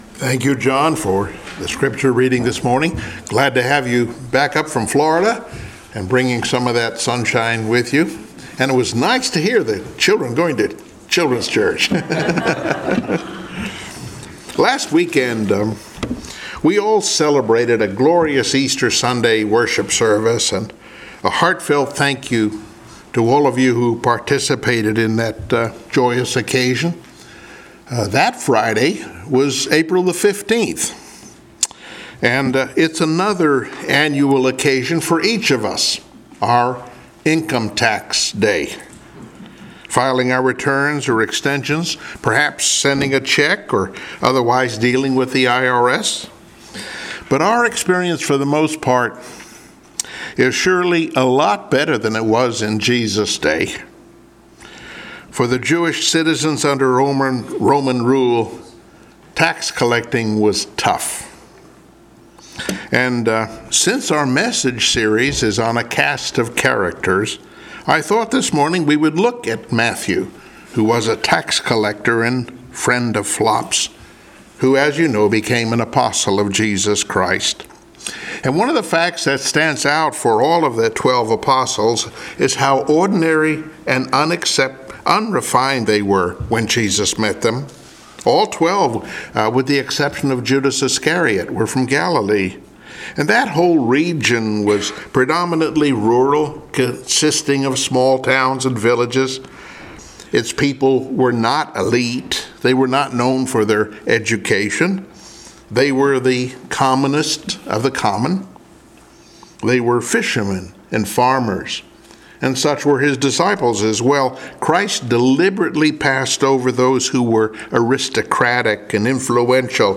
Passage: Mathew 9:9 Service Type: Sunday Morning Worship